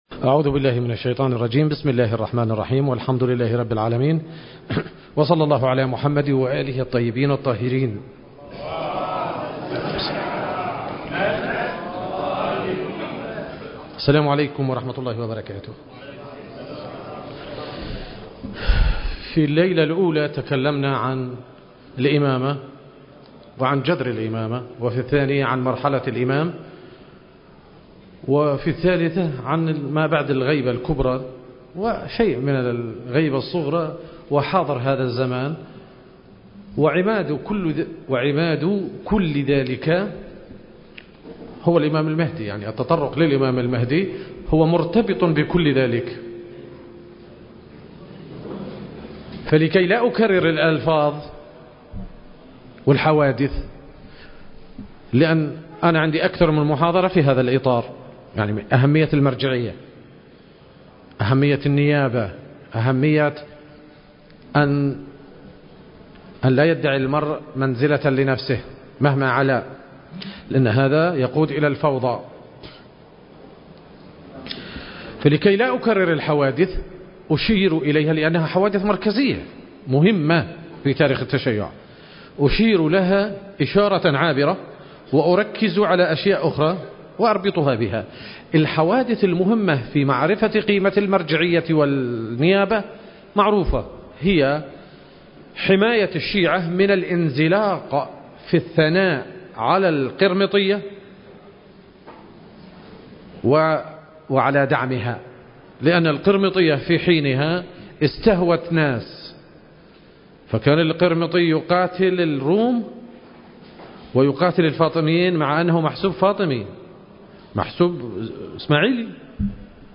المكان: الحسينية الحيدرية / الكاظمية المقدسة التاريخ: 2022